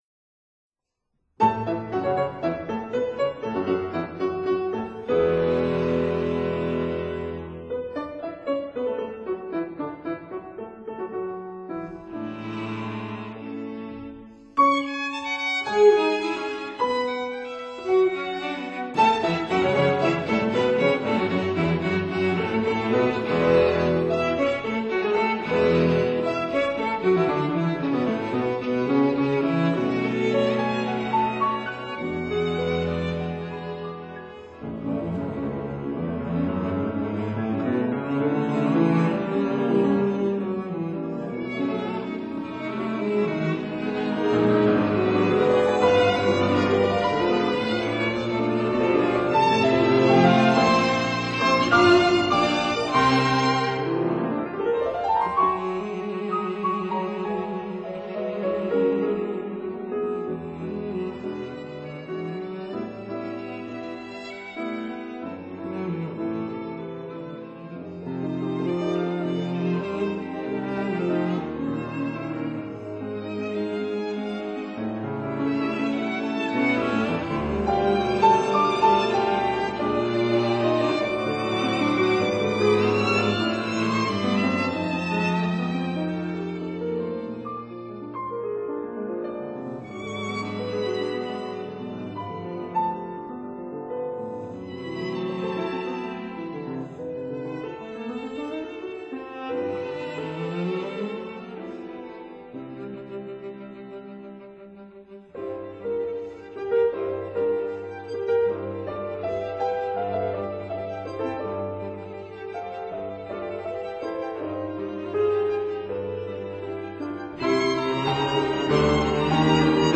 violin
viola